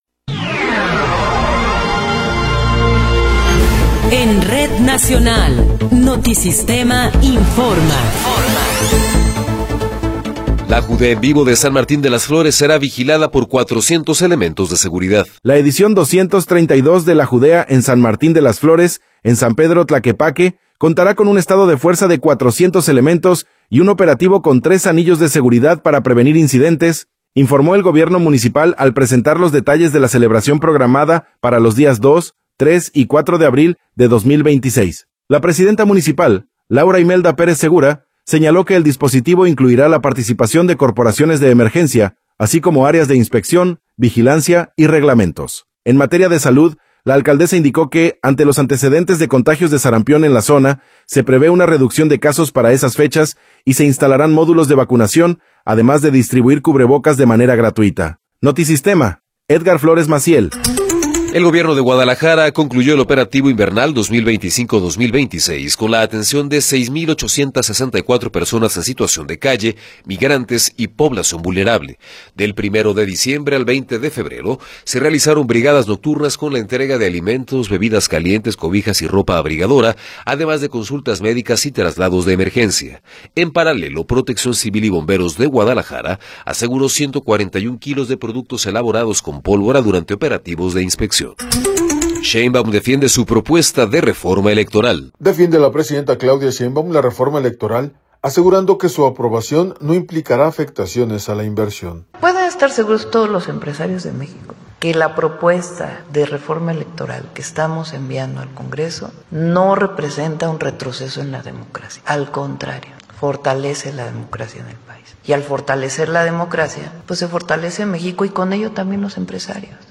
Noticiero 12 hrs. – 26 de Febrero de 2026